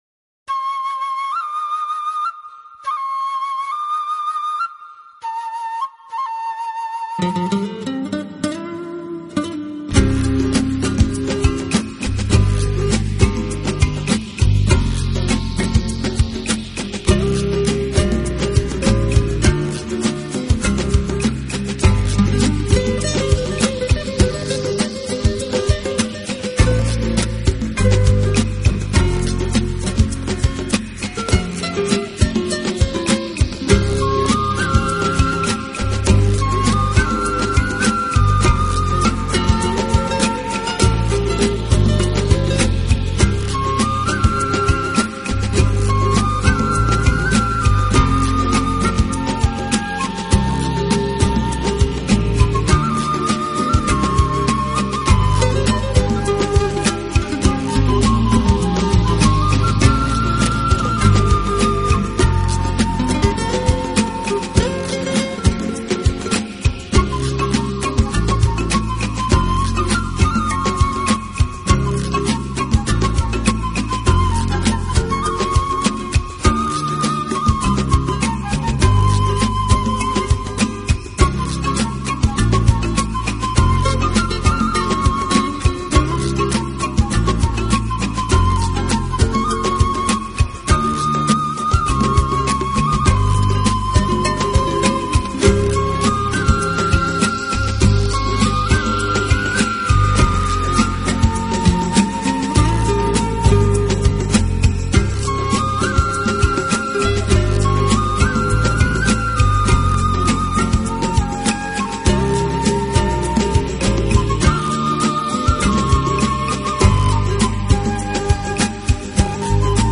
科技处理，音质更上更上一层楼，低频丰满且有弹性，高频段展现出一股律动中
的清新，宽阔的音场给听者以身临其境的惬意！